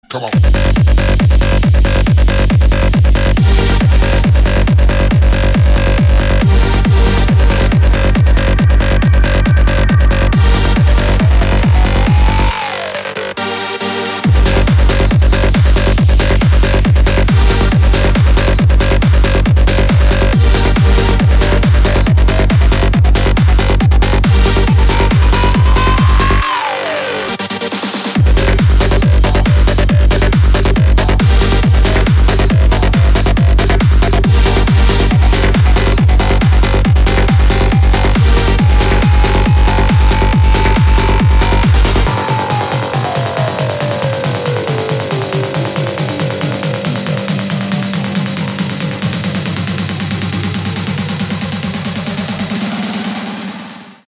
• Качество: 256, Stereo
громкие
dance
электронная музыка
Жанр: Trance.